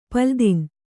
♪ paldin